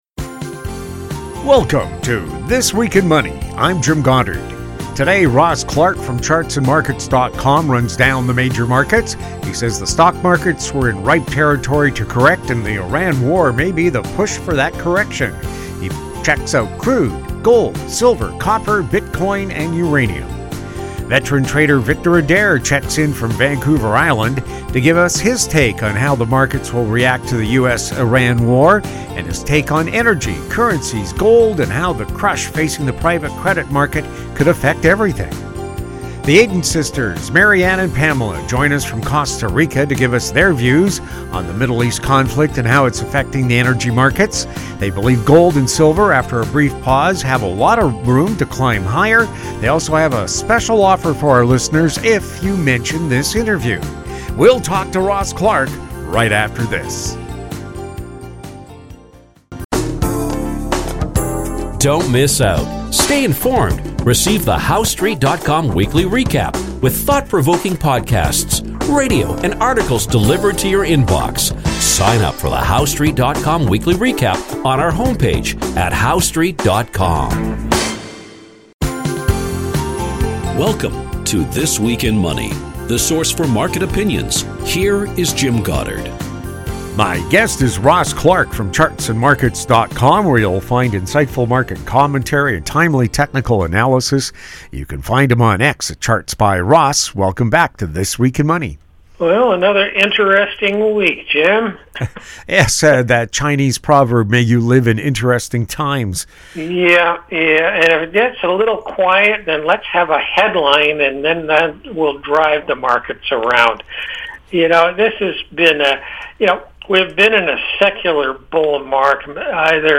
New shows air Saturdays on Internet Radio.